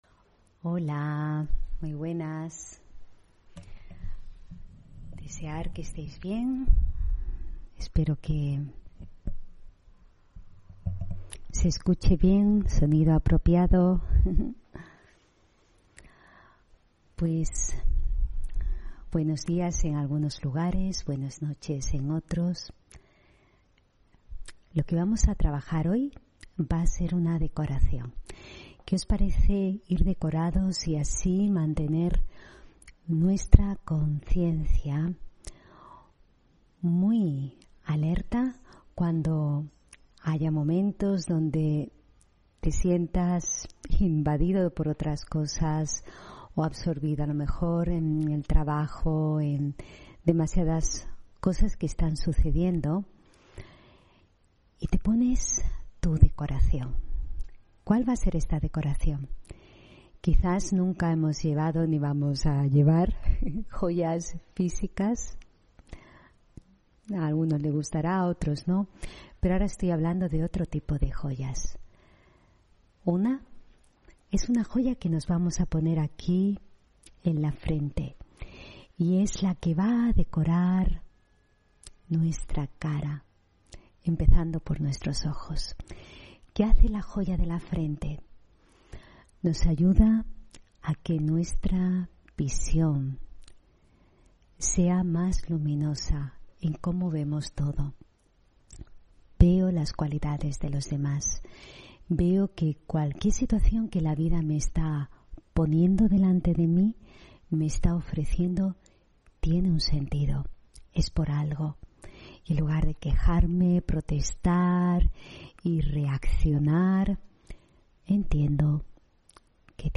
Meditación de la mañana: El espejo de la visión, las palabras y la acción